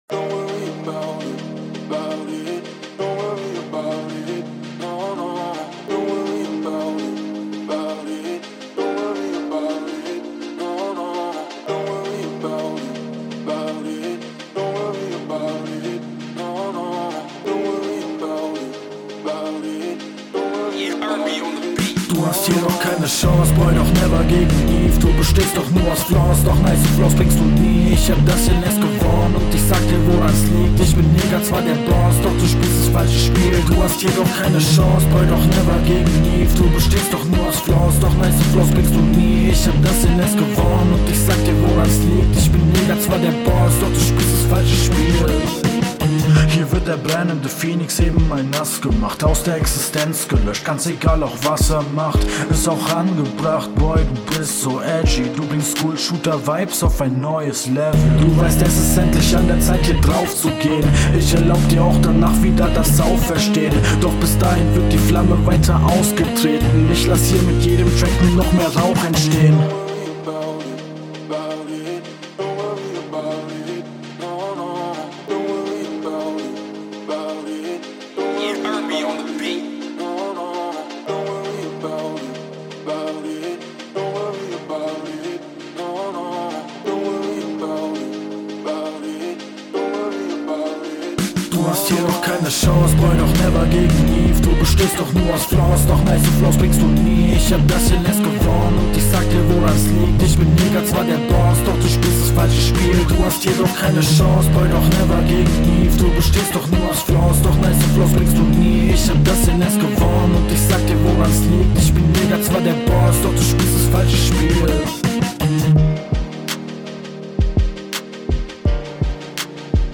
Schlimmes Pop/Rock-Instrumental :D Flow: Echt stabil für so einen Beat Text: Schoolshooter Vibes - nice.
Flow: Die Hook geht gut ins Ohr und auch der Flow im Part kommt chillig.